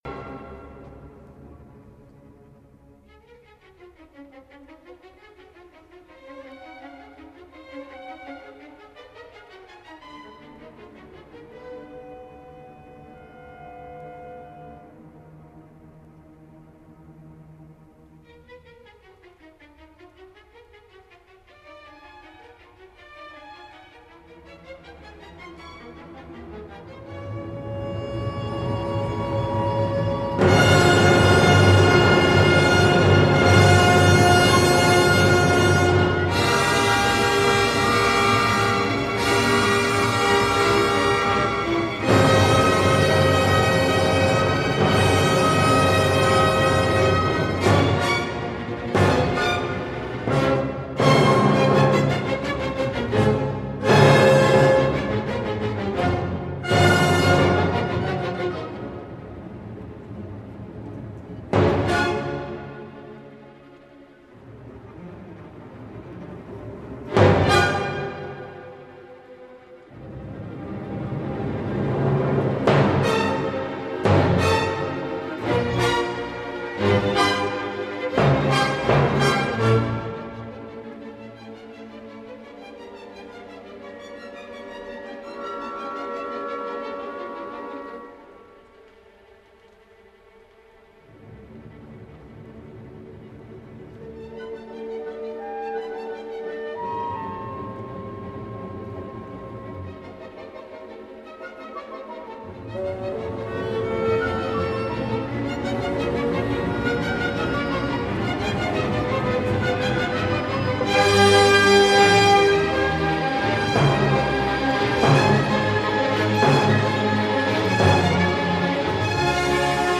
F大调